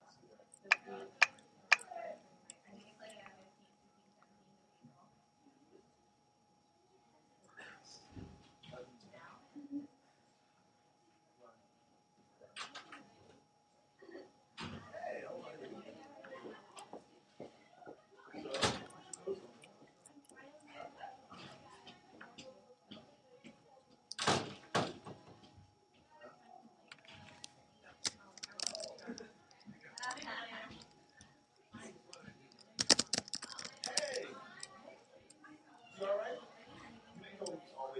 Field Recording Number Eight!
Location: Emily Lowe Hall, Hofstra University
Sounds Heard: doors opening and closing, people talking, typing, footsteps.